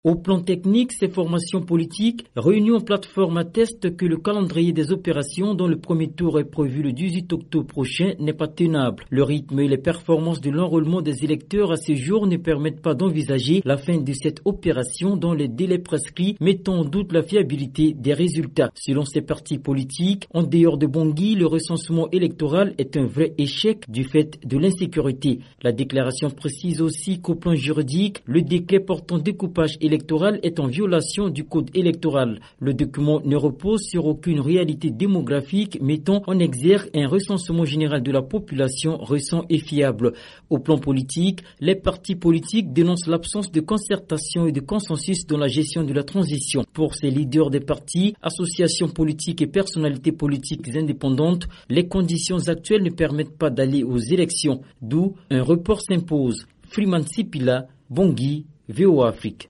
Compte-rendu